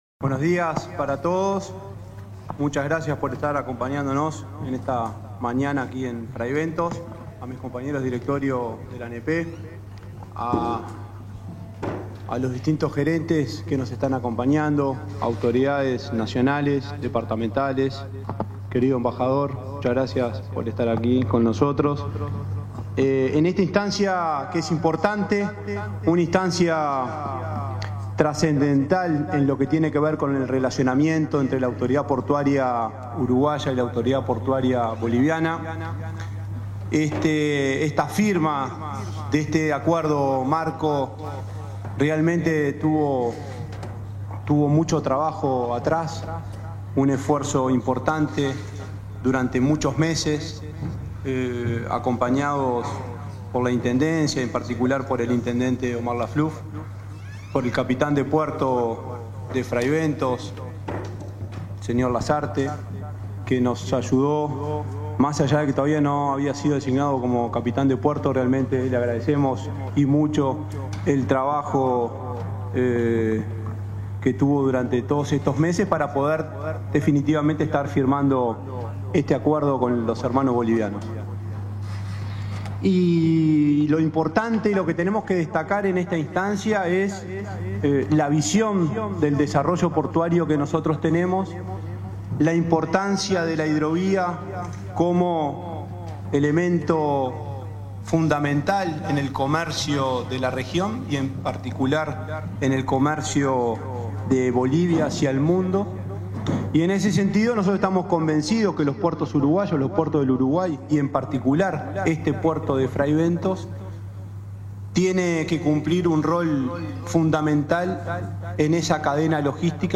Conferencia de prensa por la firman acuerdo sobre la hidrovía en Fray Bentos